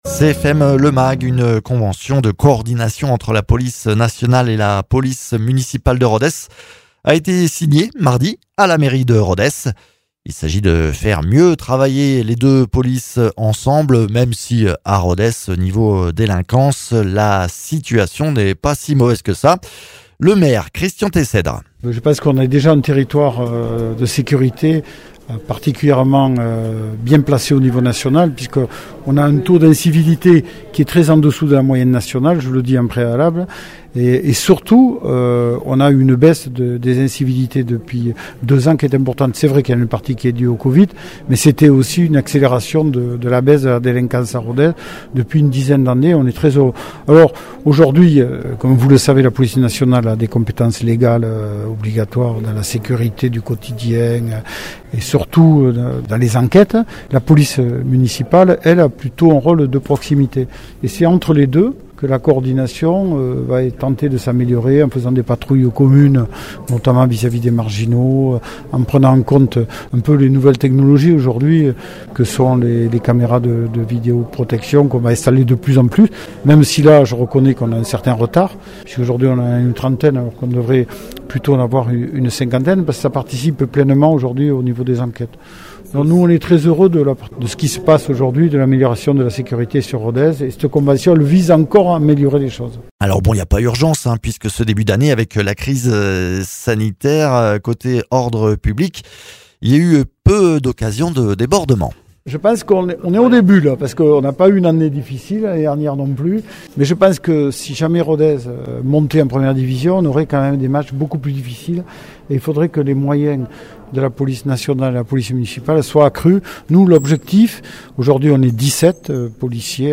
Interviews
Invité(s) : Loïc Jézéquel, Directeur départemental de la sécurité publique de l’Aveyron ; Christian Teyssèdre, Maire de Rodez ; Olivier Naboulet, Procureur de la République de Rodez.